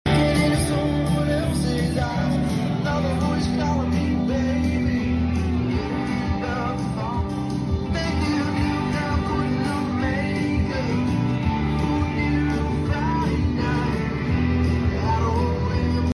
First Country Singer With Dog Sound Effects Free Download